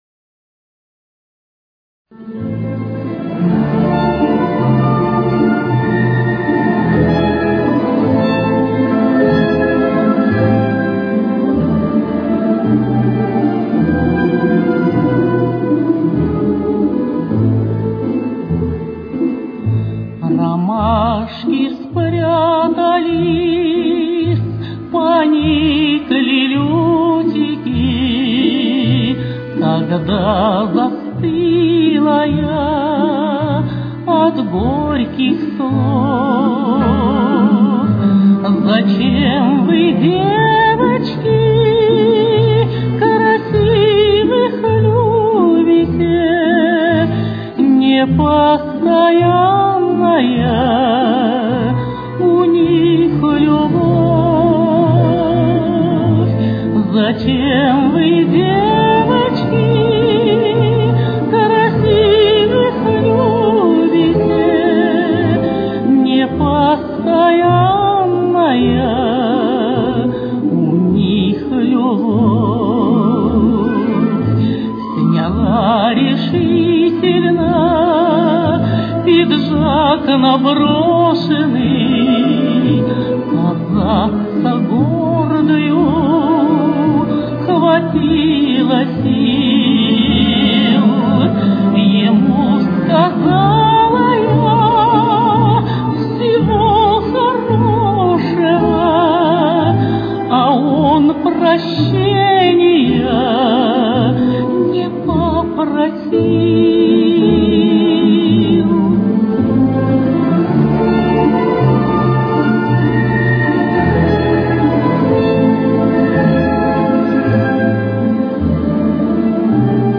с очень низким качеством (16 – 32 кБит/с)
Ля минор. Темп: 54.